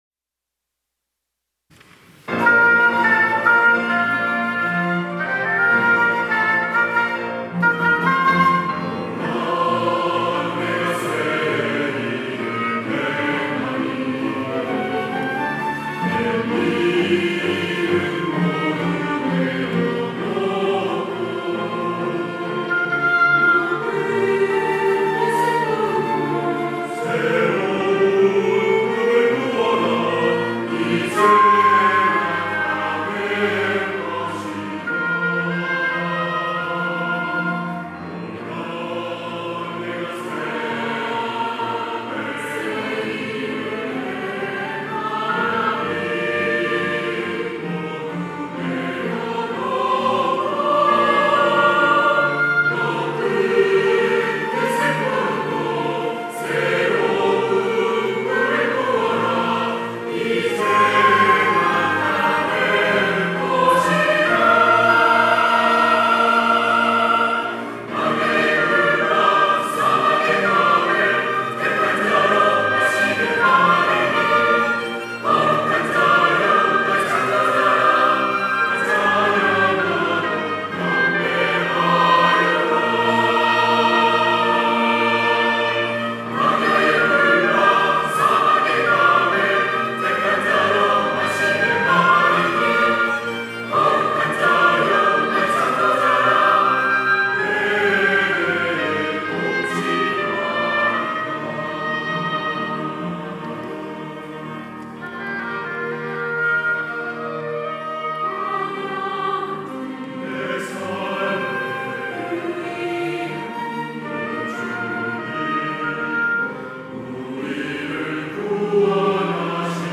할렐루야(주일2부) - 주를 보라
찬양대